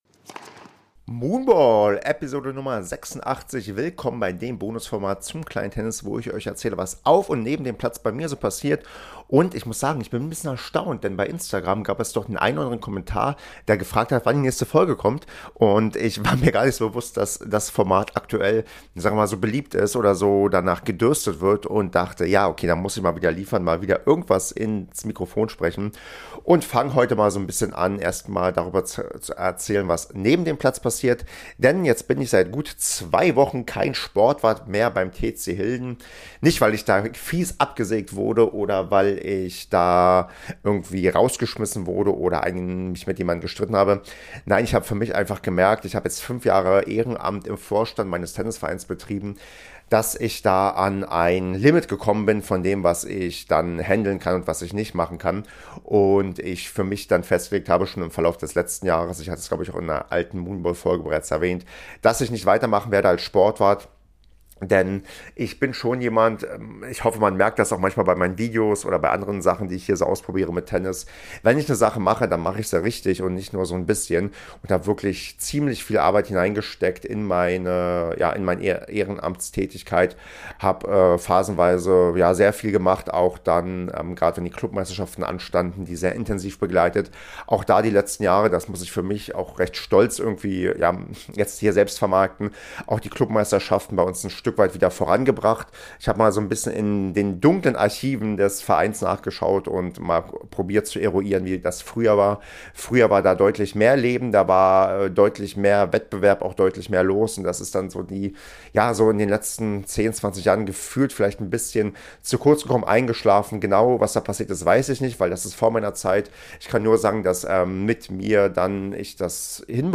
Moonball - Episode 86 (XXL-Monolog) ~ Kleines Tennis Podcast